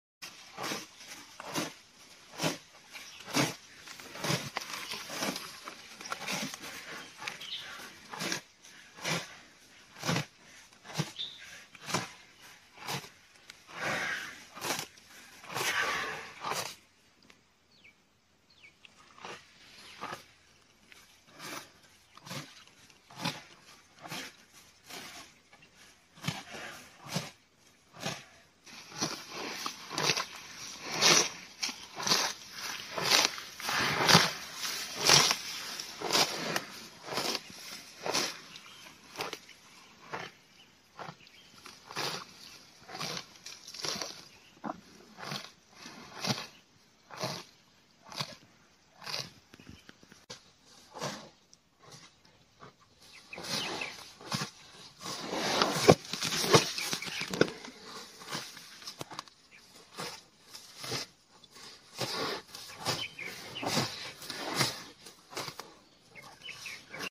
Nature's Harmony: ASMR Sounds of a Grazing Cow